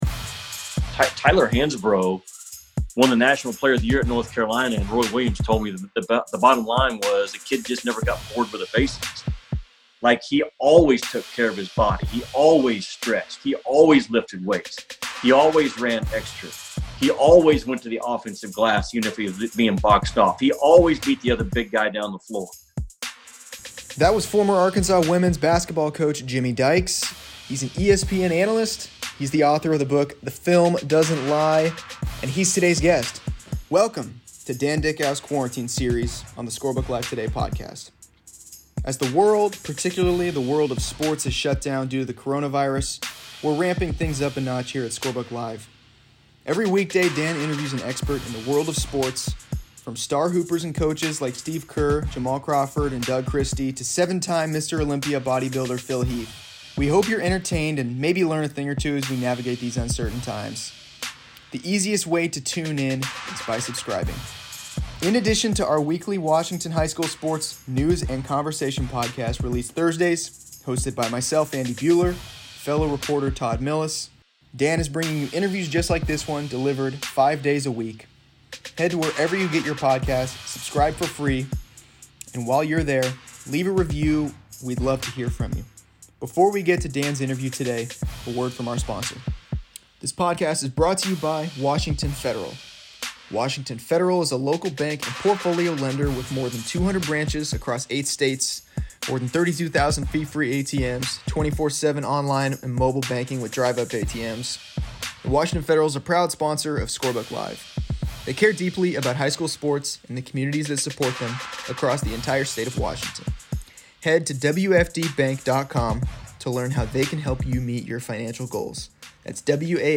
Each weekday, Dickau releases an interview with a wide range of experts in the world of sports. On Thursday, ESPN analyst and former Arkansas women's basketball coach Jimmy Dykes joins. He's the author of a new book called "The Film Doesn't Lie."